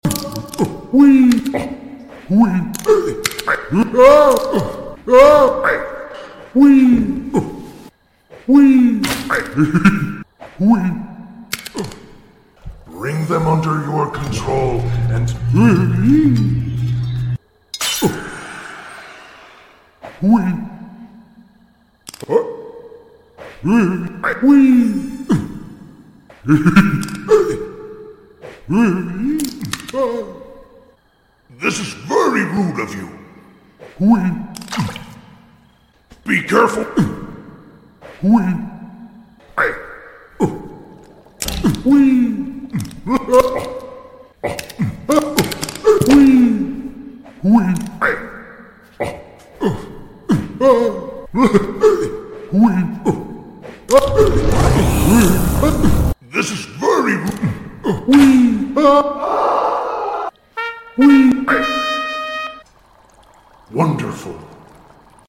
it's just the Skull saying "WEEEEEEE" a lot Waltz of the Wizard